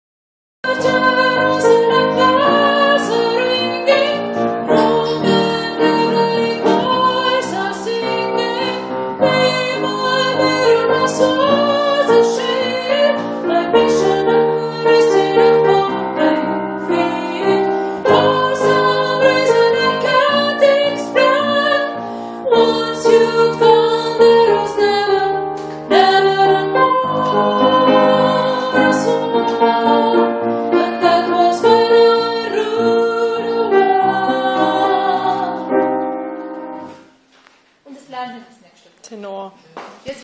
Viva-La-Vida-Refrain-Tenor.mp3